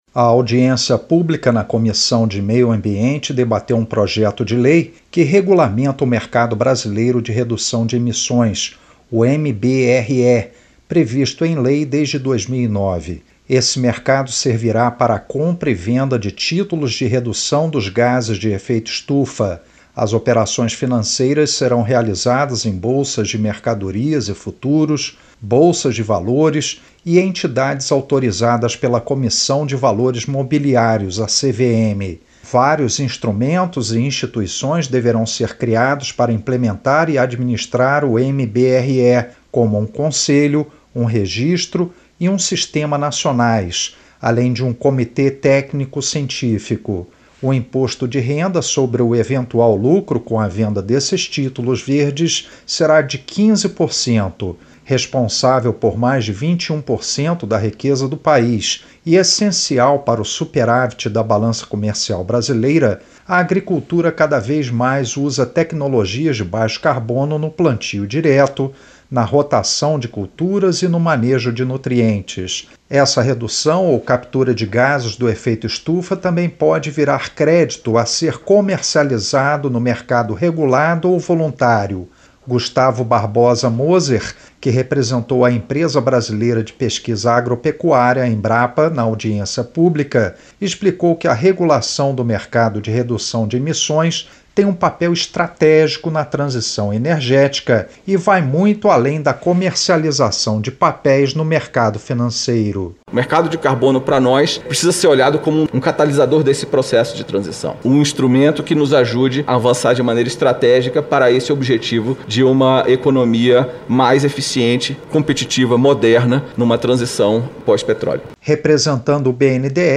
O Brasil deveria aproveitar melhor tecnologias como transformação de resíduo em energia no mercado de carbono e redução dos gases de efeito estufa, ganhando bilhões de reais. O assunto foi debatido na Comissão de Meio Ambiente do Senado.